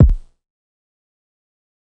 TM SIZZ KICK.wav